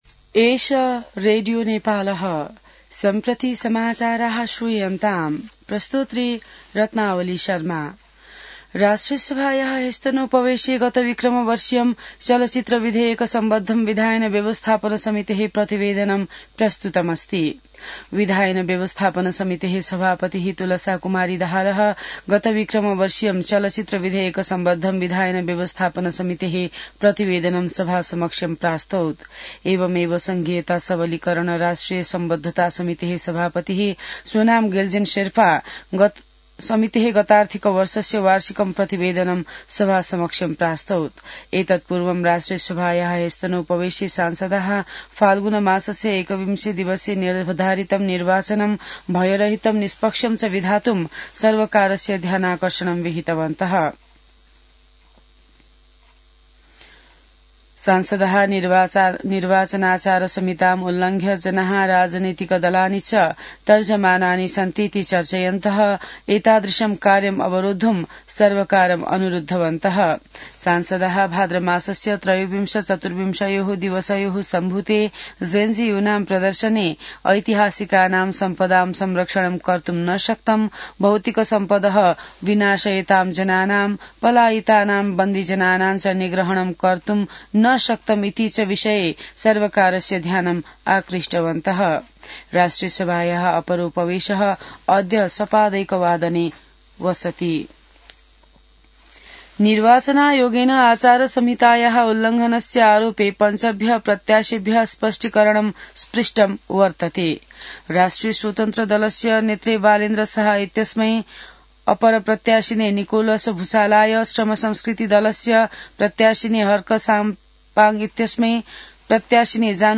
संस्कृत समाचार : १४ माघ , २०८२